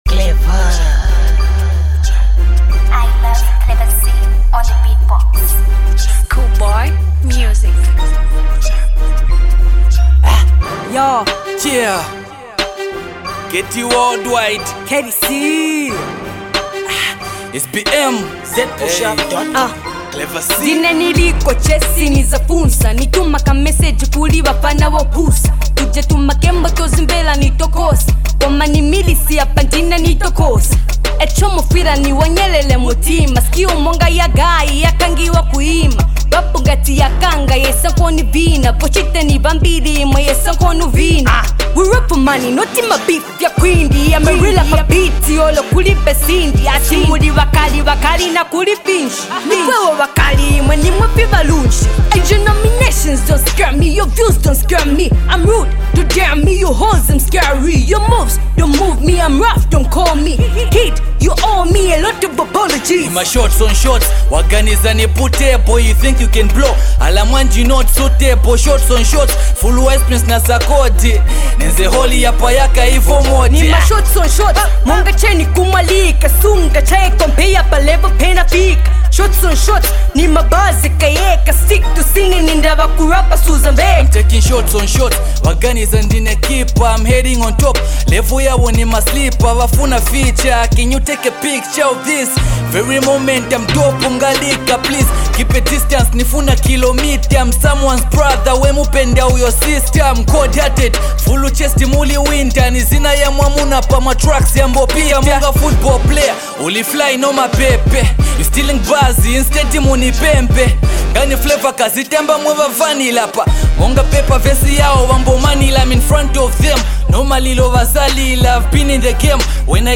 fire hiphop joint
kindly get to download and witness real hiphop.